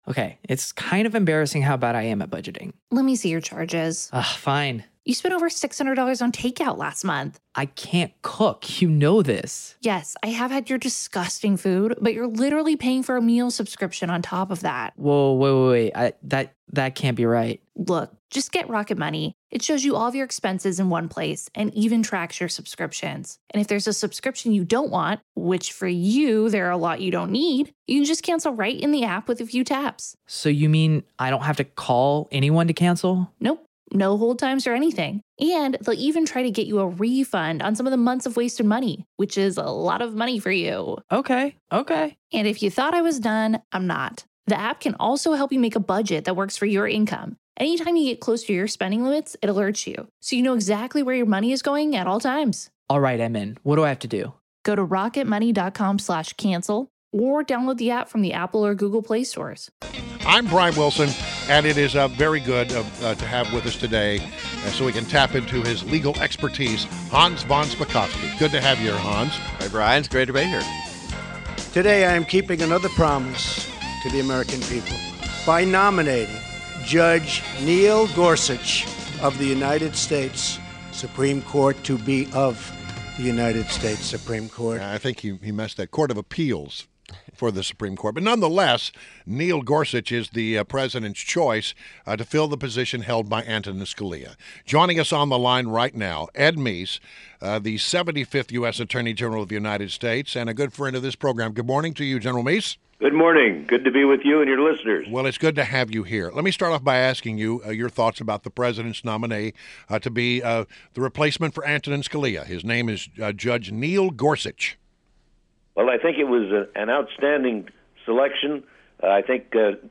INTERVIEW – ED MEESE — Former 75th U.S. Attorney General of the United States (1985–1988) in the President Reagan administration and currently The Heritage Foundation’s Ronald Reagan distinguished fellow emeritus